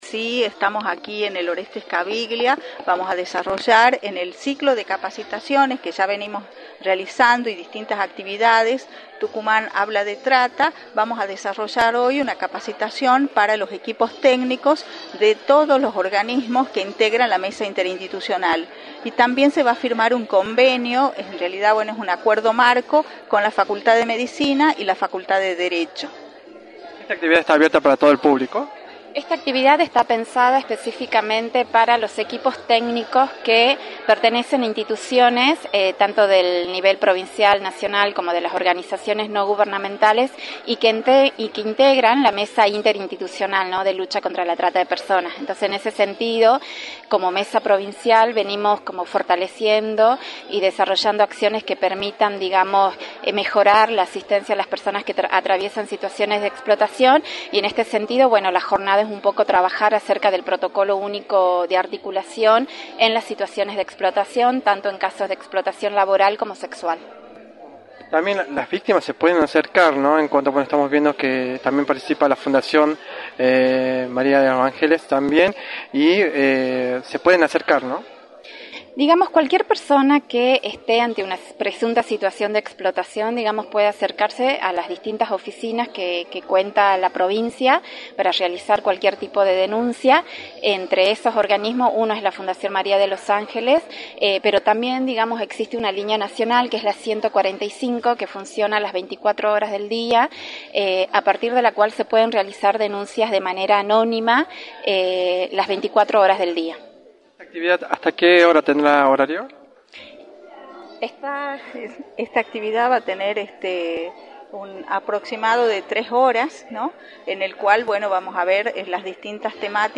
“Venimos fortaleciendo y desarrollando acciones que permitan mejorar la asistencia a personas que padecen situaciones de explotación” explicaron los referentes de esta capacitación en entrevista para Radio del Plata Tucumán, por la 93.9.